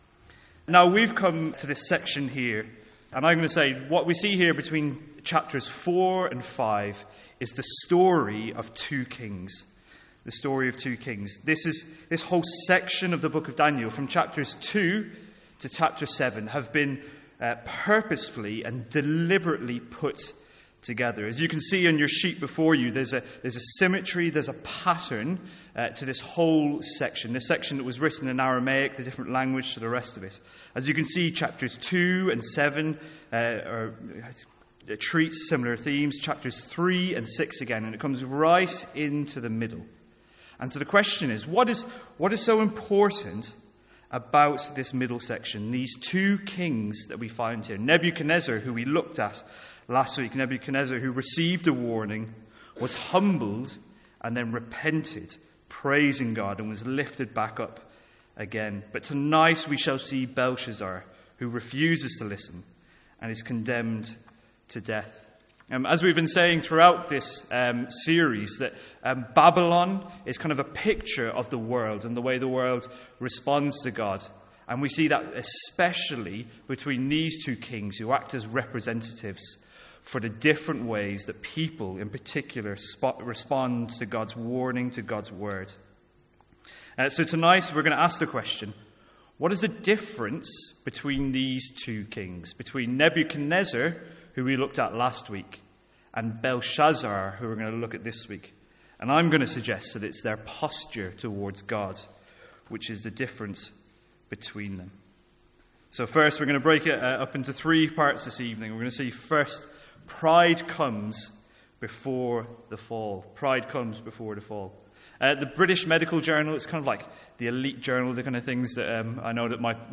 Sermons | St Andrews Free Church
From our evening service in Daniel.